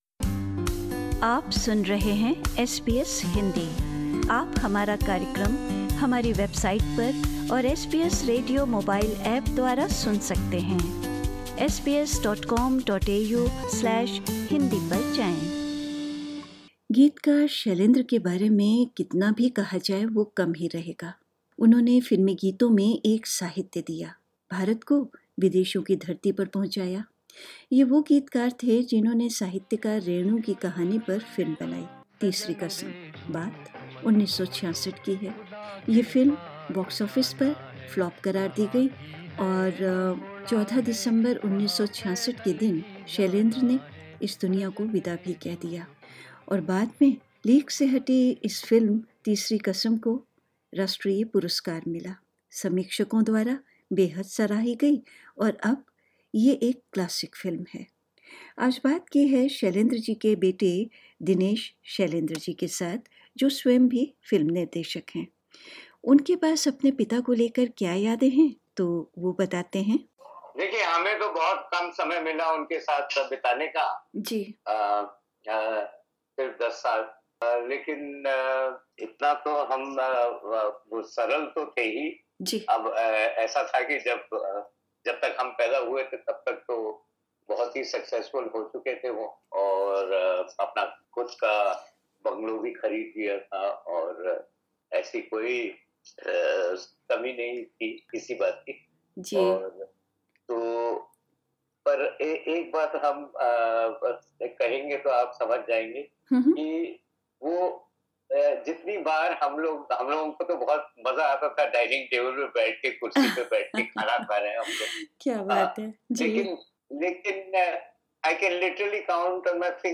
बातचीत